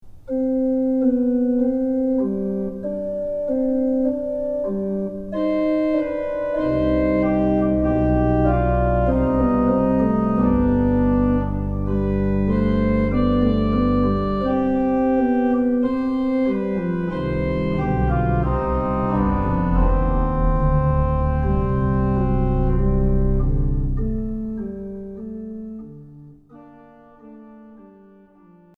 urkukoraalia